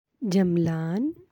(jamalān)